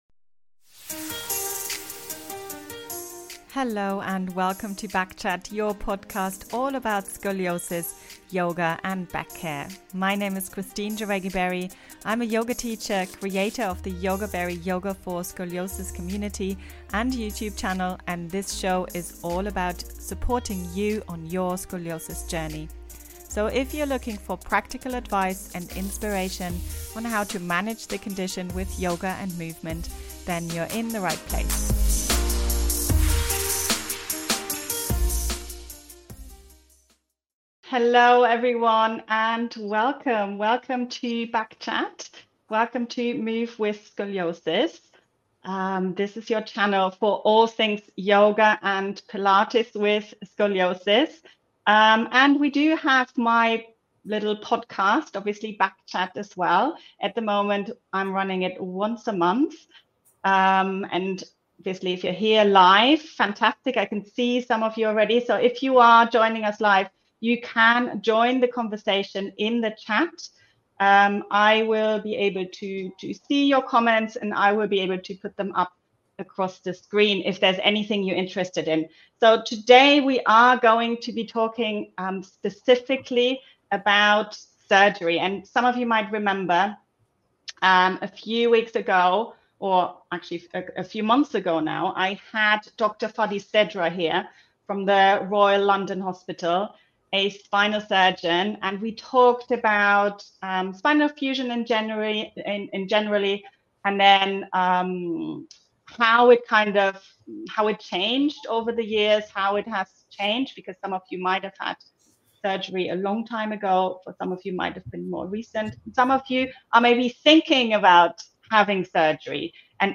73. Do you really need spine surgery – Interview